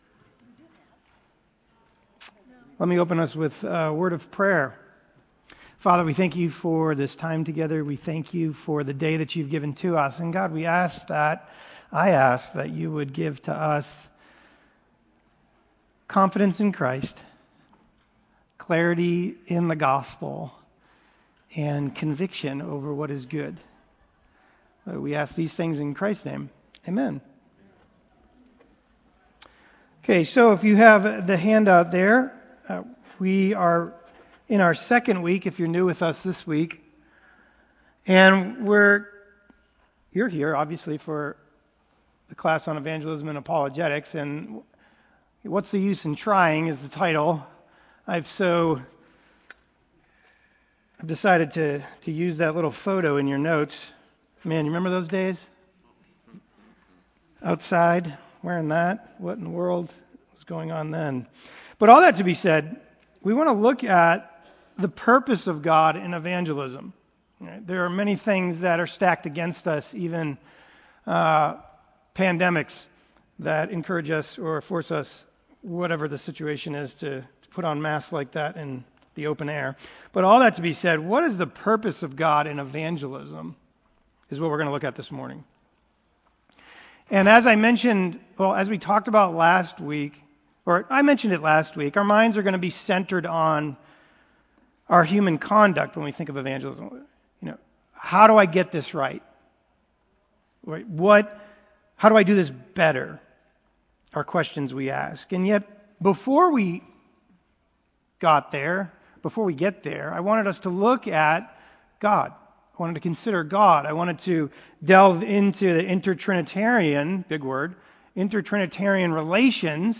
Sunday School Classes
Lesson-2-Whats-the-Use-in-Trying.mp3